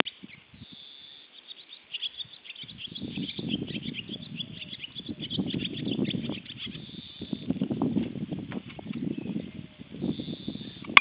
Zwitschern von einem Vogel / Chirping of a Bird
Ich hatte diese Art von Zwitschern noch nie gehört. / I hadn't heard this type of song before.